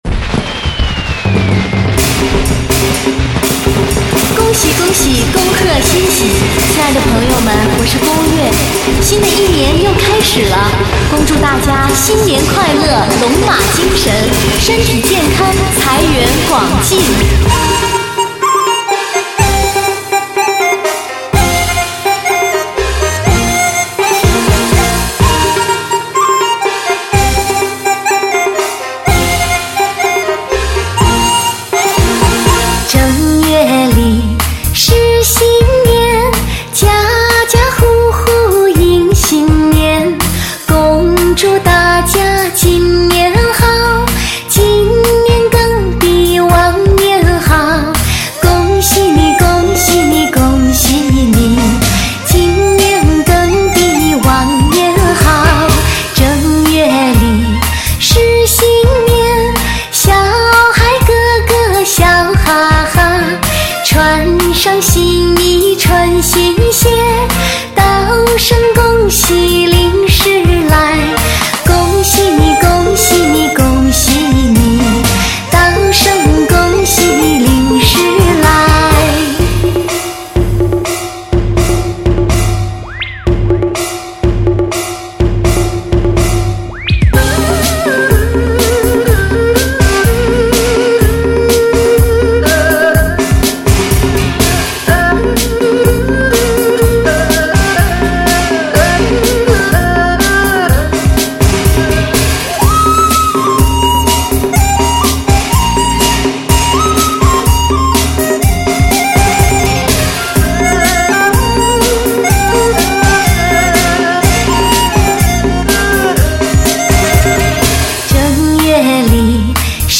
最火红贺岁金曲，发烧珍藏